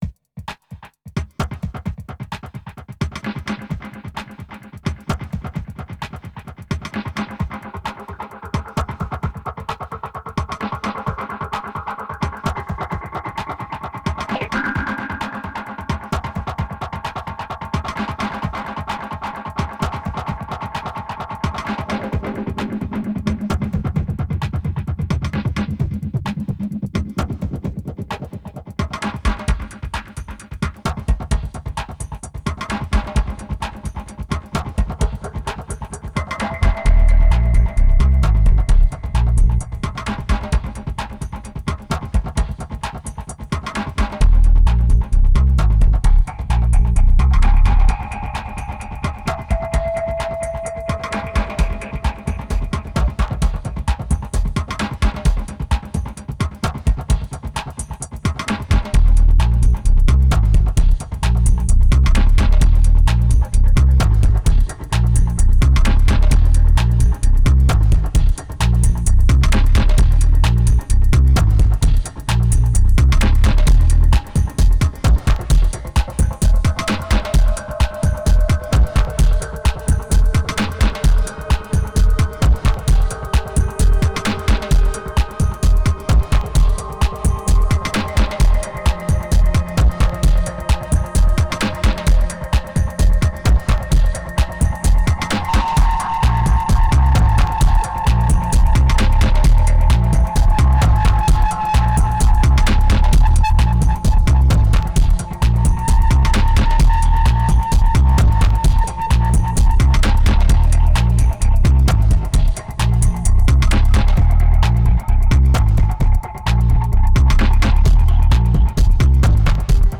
It's raw, it's dirty and it's lame, but are you up to it ?